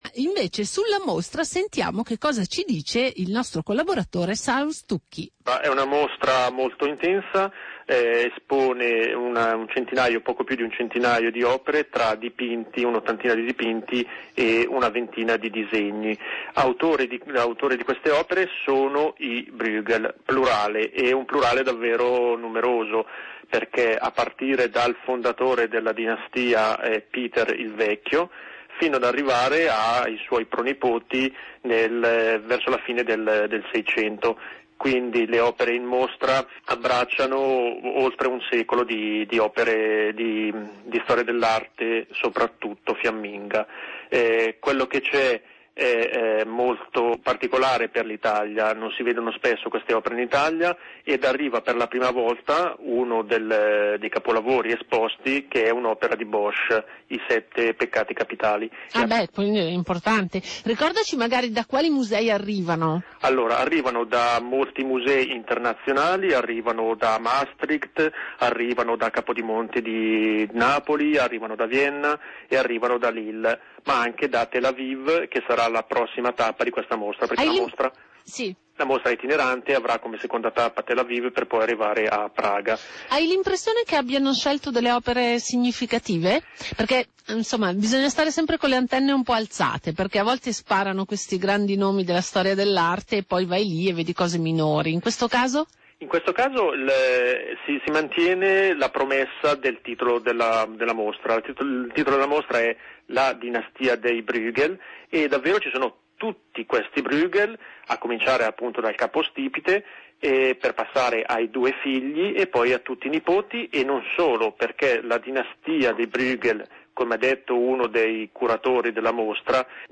Radio Popolare I girasoli, condotta in studio